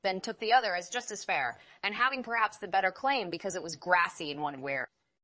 tortoise-tts - (QoL improvements for) a multi-voice TTS system trained with an emphasis on quality